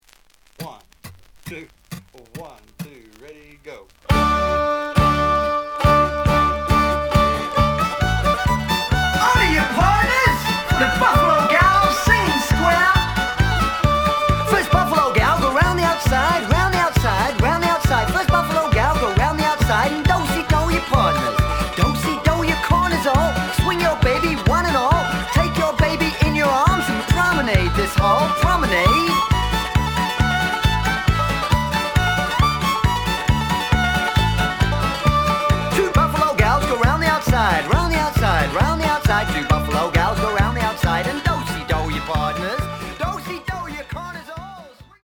The audio sample is recorded from the actual item.
●Genre: Hip Hop / R&B